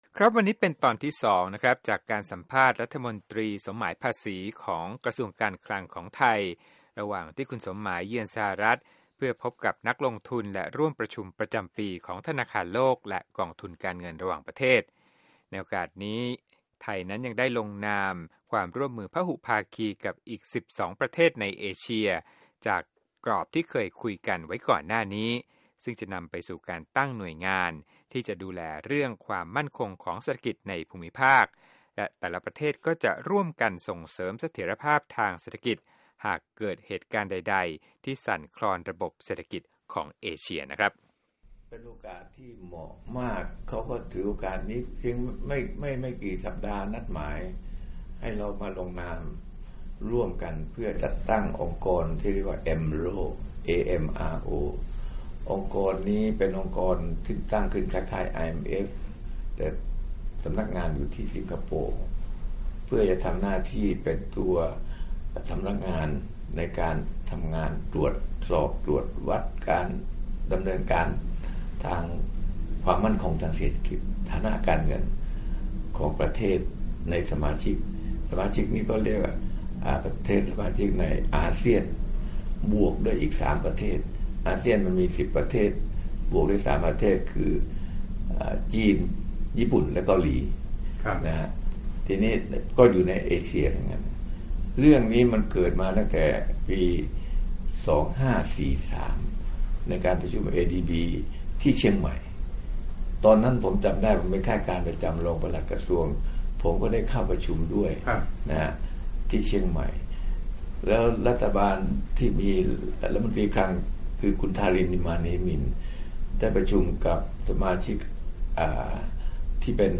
สัมภาษณ์พิเศษ: รัฐมนตรีคลัง สมหมาย ภาษีได้ลงนามความร่วมมือกับอีก 12 ประเทศในเอเชียเพื่อเสริมความมั่นคงทางการเงินภูมิภาค
Sommai Interview 2